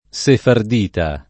sefardita [ S efard & ta ]